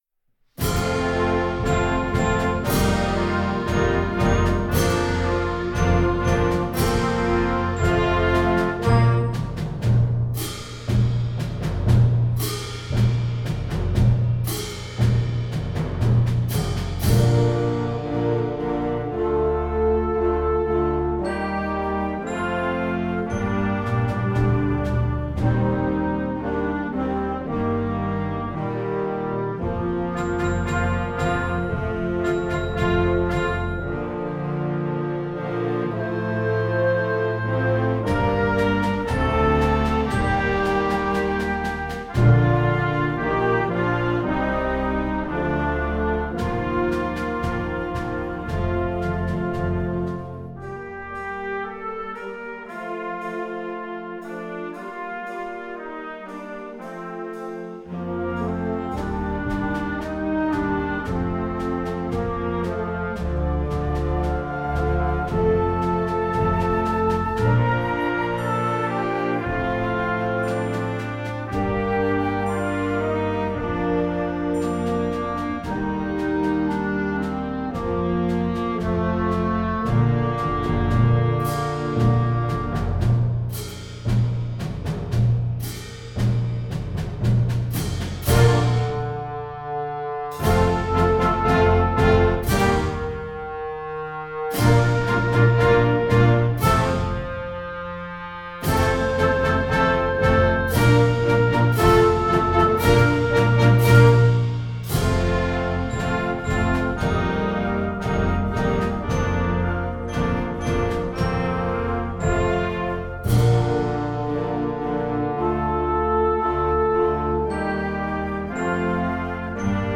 Gattung: Konzertwerk für Jugendblasorchester
Besetzung: Blasorchester
unterhaltsames Klarinetten-Feature